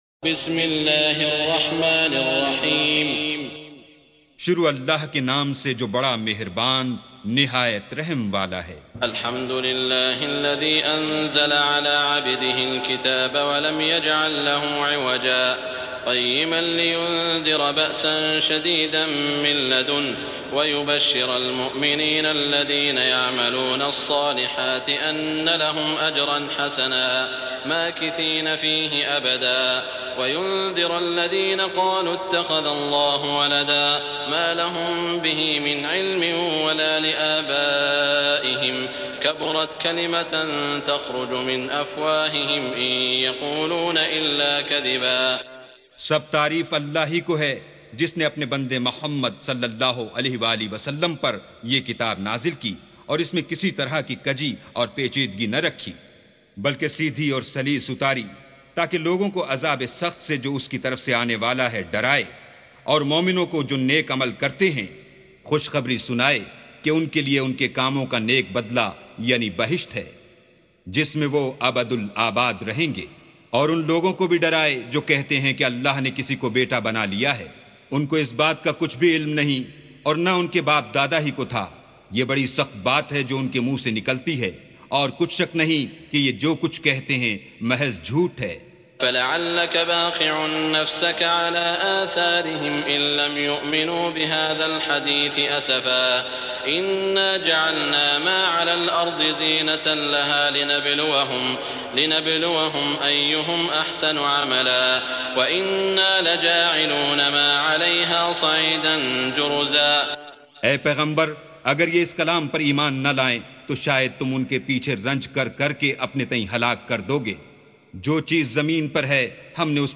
سُورَةُ الكَهۡفِ بصوت الشيخ السديس والشريم مترجم إلى الاردو